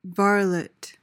PRONUNCIATION:
(VAR-luht/lit)